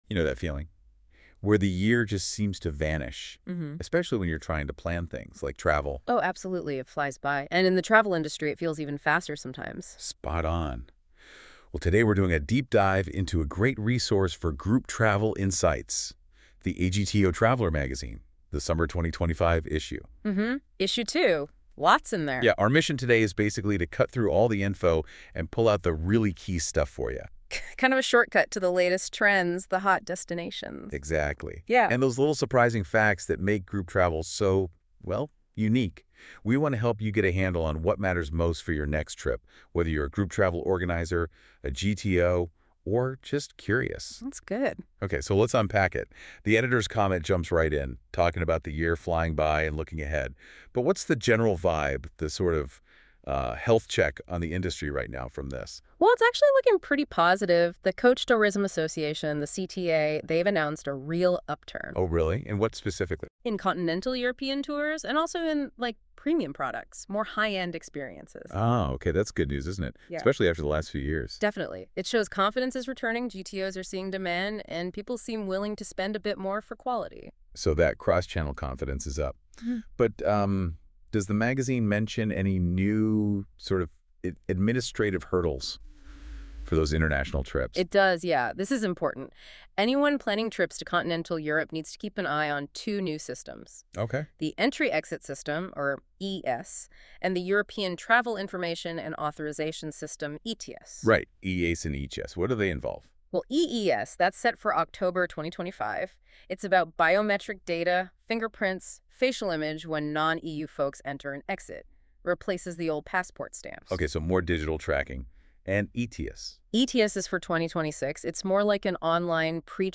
This podcast, produced using a publicly available AI tool and featuring two entirely AI-generated presenters, discusses the Summer 2025 edition of AGTO Traveller magazine.
The AI tool has been developed in the US, so the narration is delivered in American accents and often using American terms.